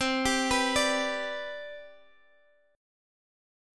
Listen to CM7sus2 strummed